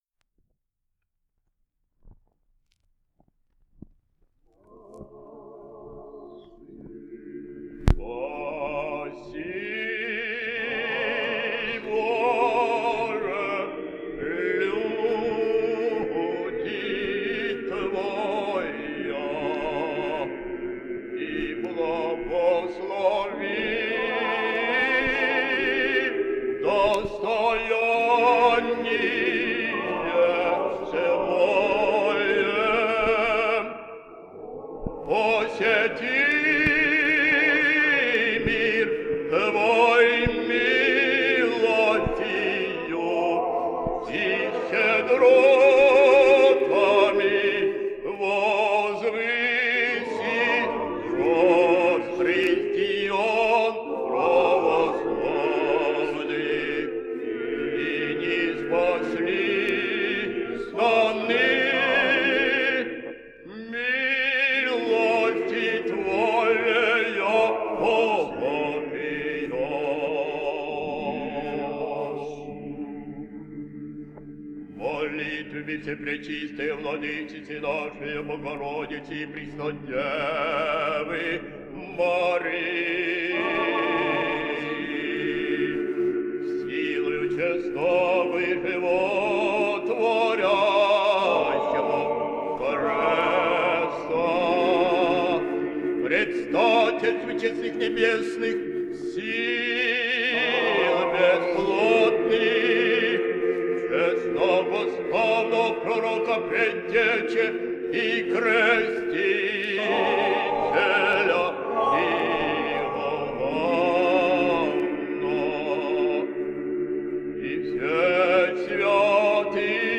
The recording is that of older singers, who’s voices are not as fresh or supple as they once were